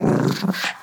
Minecraft Version Minecraft Version latest Latest Release | Latest Snapshot latest / assets / minecraft / sounds / mob / wolf / grumpy / growl1.ogg Compare With Compare With Latest Release | Latest Snapshot
growl1.ogg